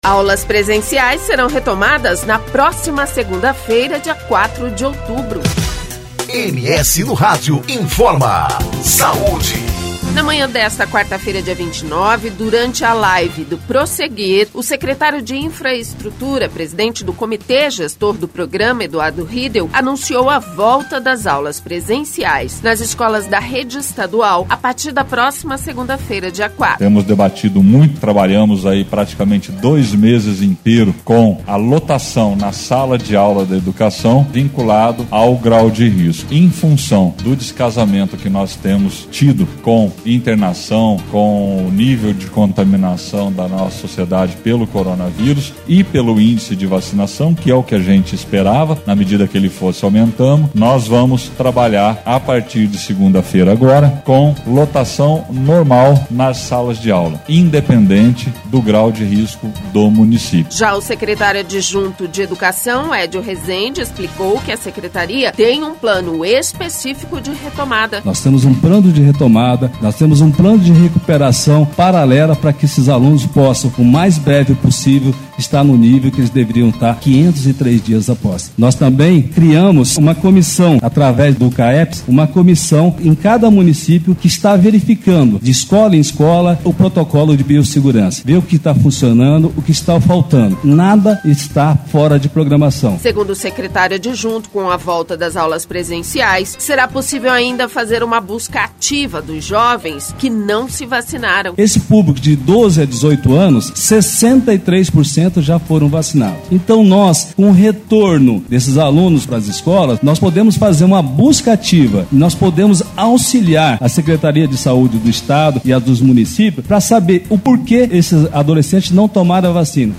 Na manhã desta quarta-feira, dia 29, durante a live para divulgação do boletim epidemiológico, secretário de infraestrutura, presidente do Comitê Gestor do Prosseguir, Eduardo Riedel, anunciou a volta das aulas presenciais nas escolas da rede estadual a partir da próxima segunda-feira, dia 04.